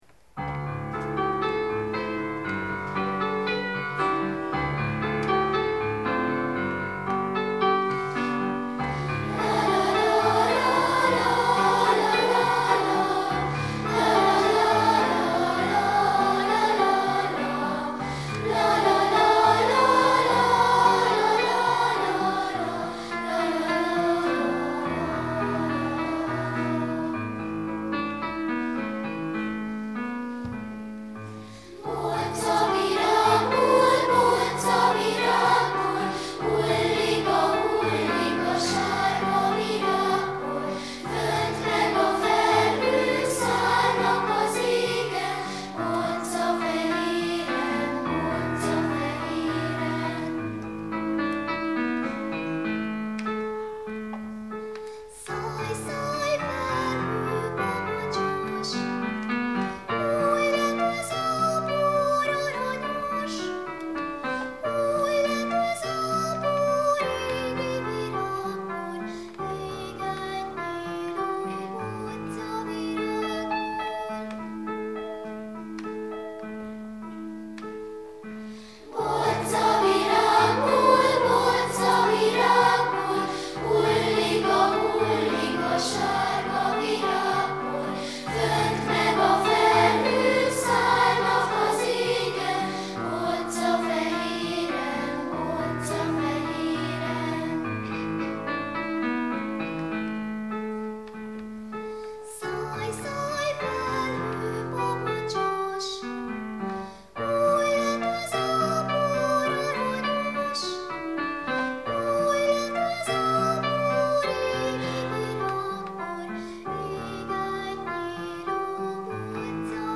A felvétel a Lajtha László Zeneiskola millenniumi hangversenyén készült 2001. április 4-én.
zongorán kísér
szólót énekel